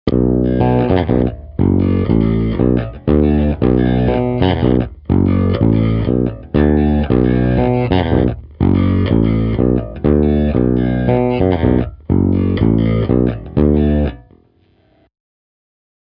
Je to pořízené přes iPad v GarageBandu přes Clean Combo.
Kobylkový snímač